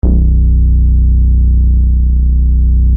Garage Chub Bass MS 20 B1 chub_bass
chub_bass.mp3